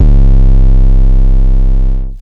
TM88 KillerDist808.wav